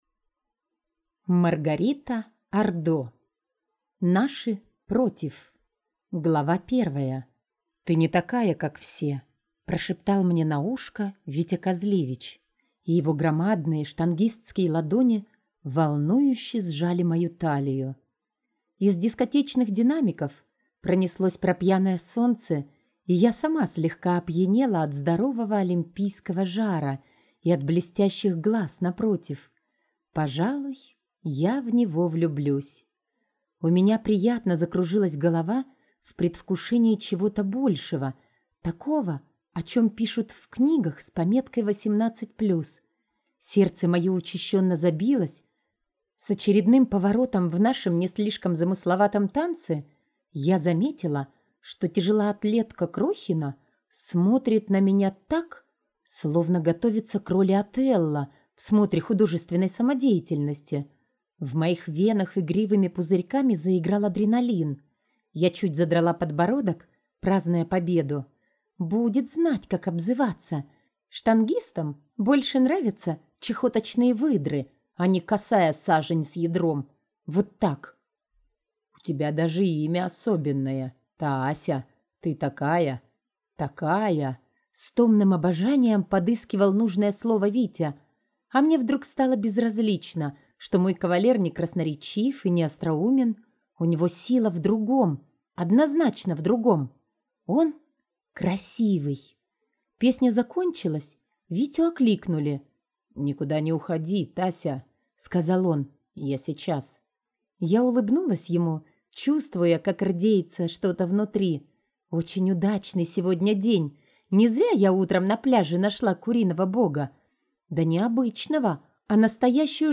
Аудиокнига Наши против | Библиотека аудиокниг